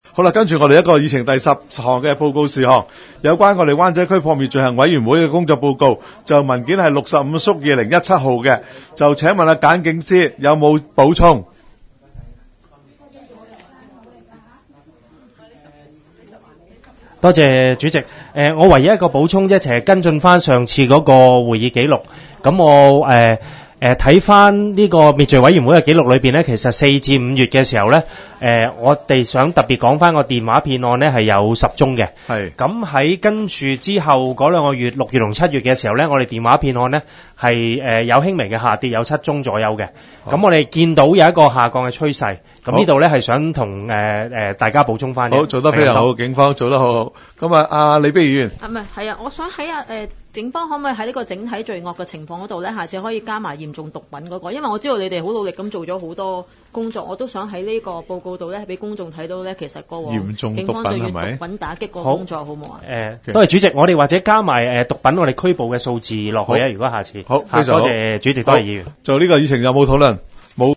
区议会大会的录音记录
湾仔区议会第十二次会议